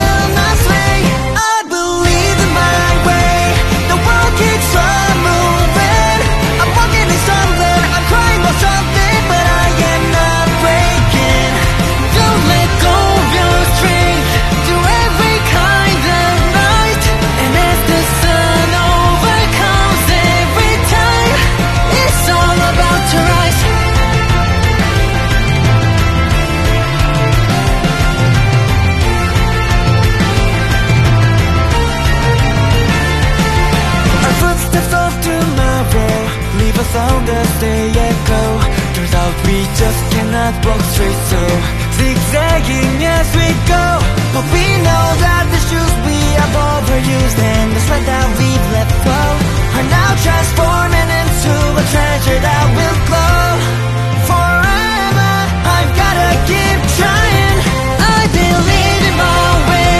live performance